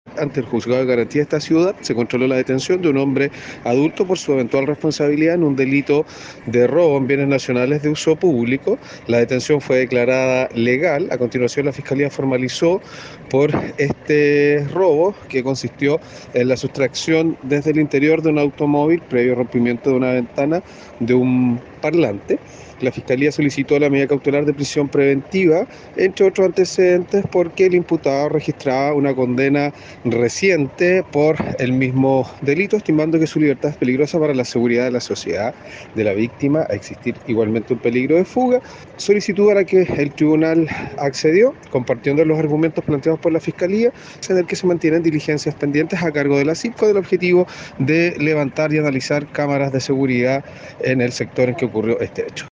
Desde el ministerio Público, el fiscal Fernando Metzner, manifestó que el sujeto paso a control de la detención y formalización, ocasión en que se solicitó la prisión preventiva, lo que fue acogido por el Juzgado de Garantía, ya que en su prontuario se consignaban antecedentes similares.